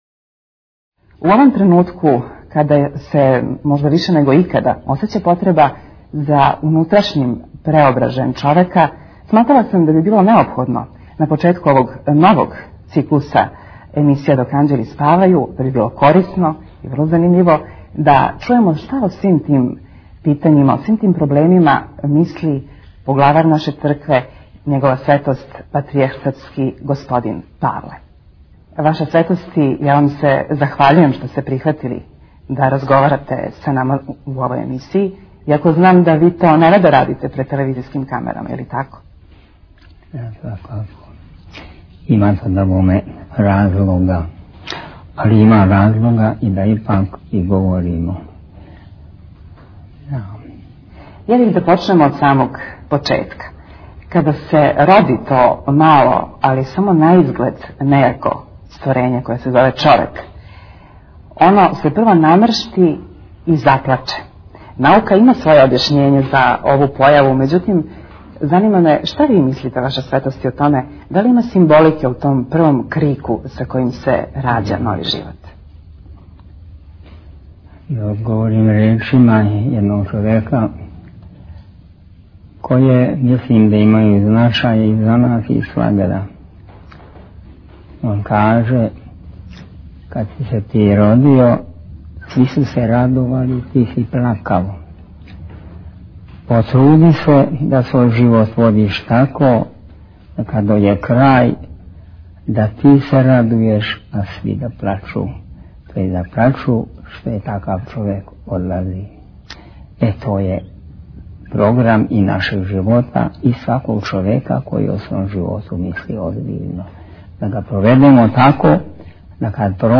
Светлост Његове Светости - Шта је Патријарх говорио за ТВ Двери Tagged: +Патријарх Павле 53:37 минута (7.68 МБ) У манастиру Благовештење у Овчар Бањи разговарали смо са Његовом Светошћу Архиепископом Пећким, Митрополитом Београдско - карловачким и Патријархом Српским Господином Павлом. Била је то прилика да од Његове Светости добијемо одговоре на нека од горућих питања која се налазе у жижи интересовања православне омладине сабране око нашег часописа.